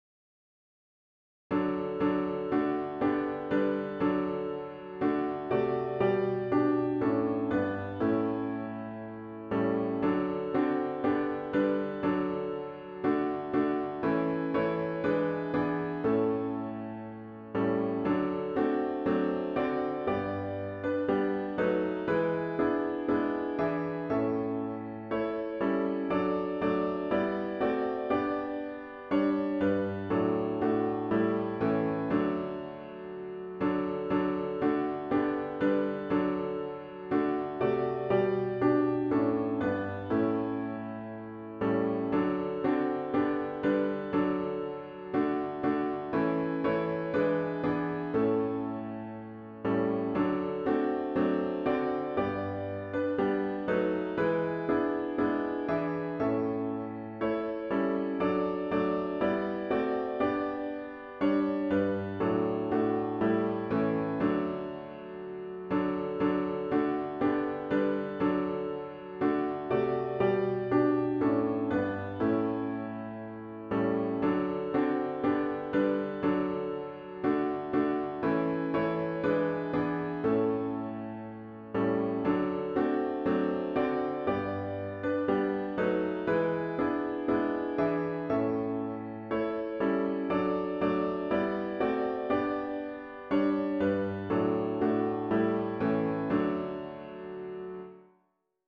OPENING HYMN   “The Day of Resurrection!”